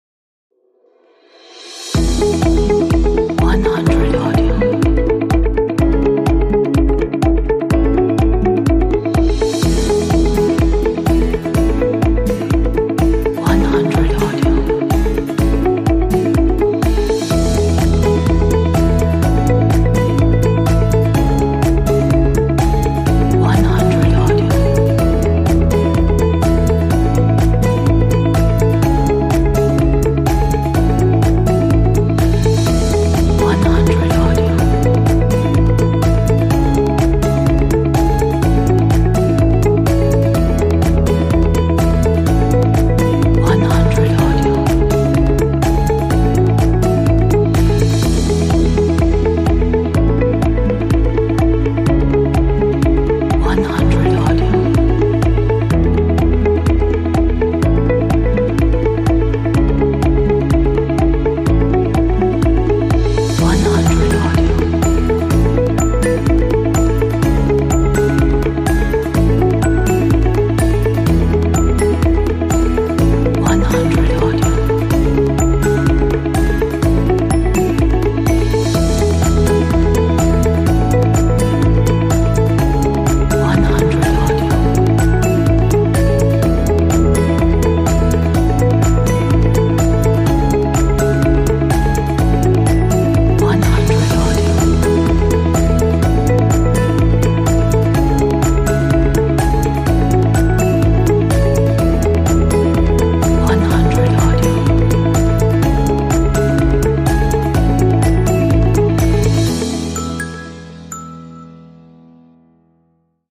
Exclusive motivation track.